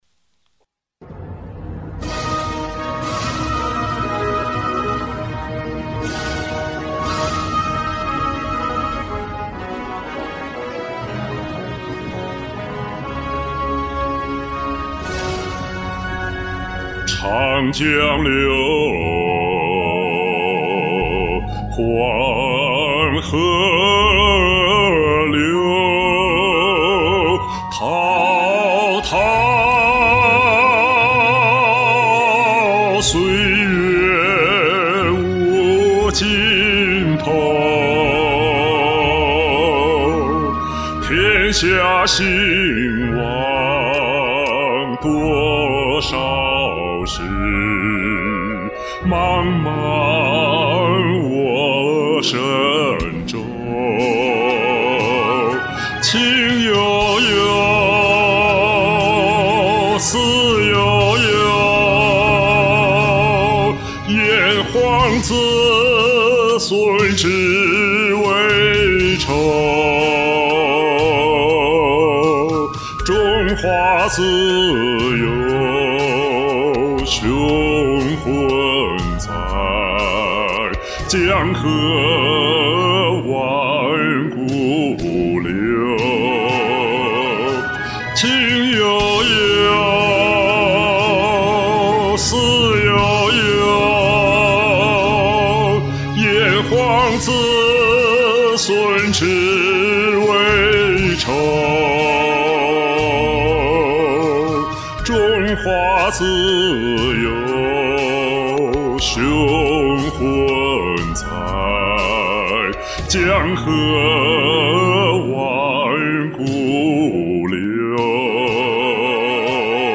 很久沒錄歌了，這個周末總算湊合了一首。